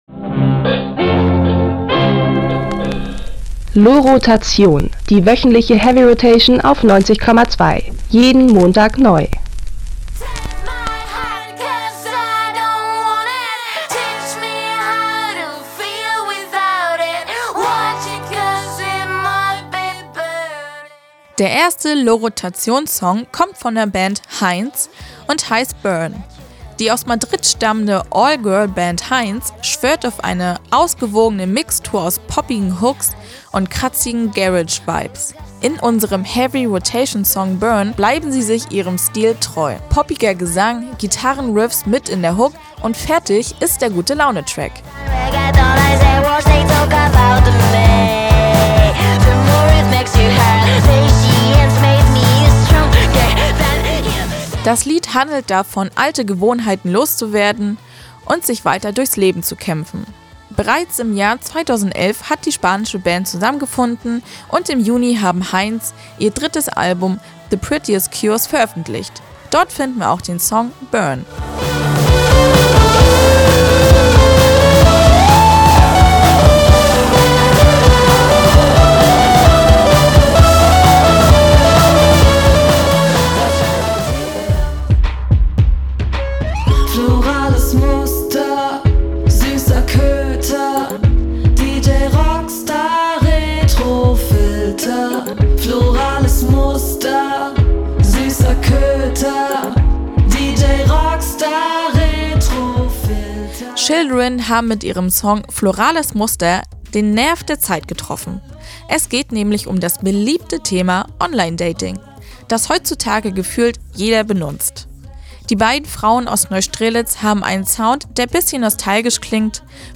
Es ist die Fusion der schneider’schen Talente  für Jazzmusik